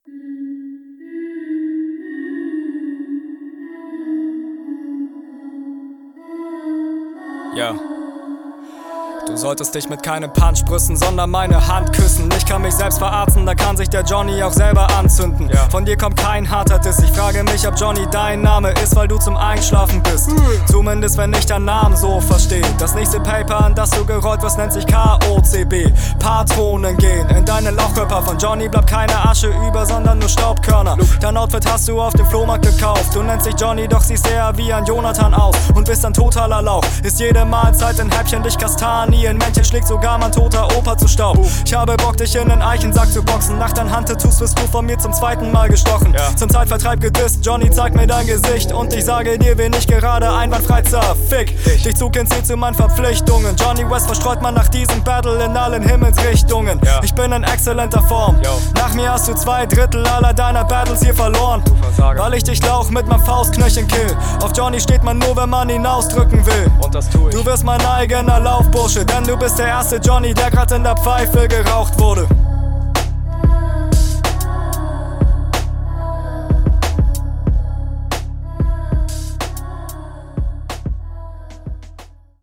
hmm, Stimmeinsatz ist nicht mehr ganz so stark wie beim Konter. bisschen chilliger aber vor …
Okay, Beat wirkt sehr atmosphärisch, mal gucken, wie du darauf kommst.